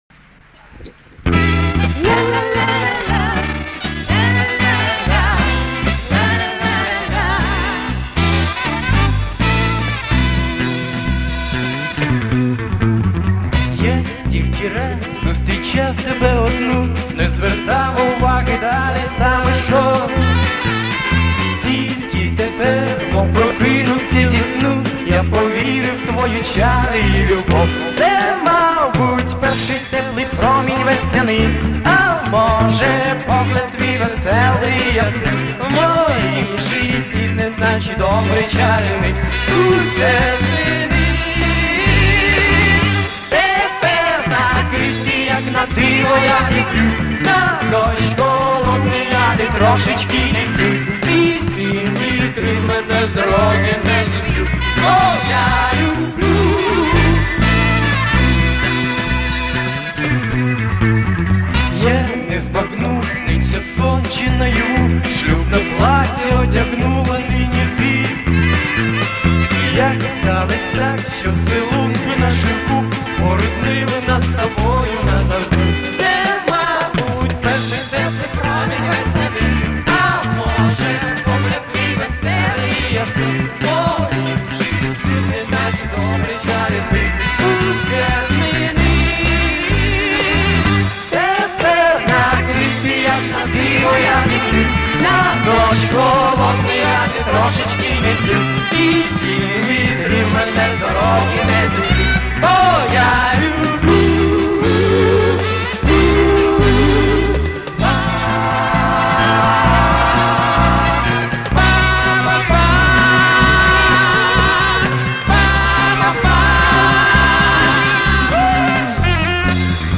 студийная запись
Качество с кассеты ещё ниже